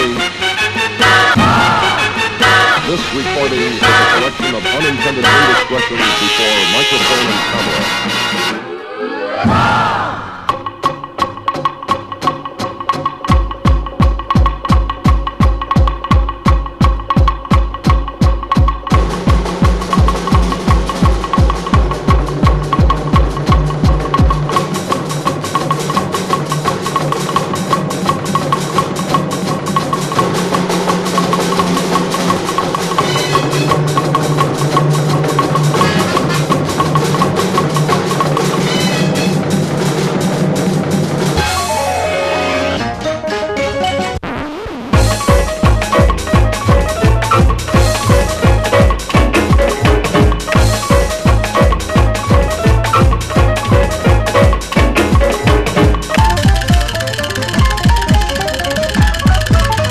ROCK / 90''S～ / ALT./EXPERIMENTAL / POST ROCK / SCI-FI
変拍子のアフリカン・サイケデリック・ジャズ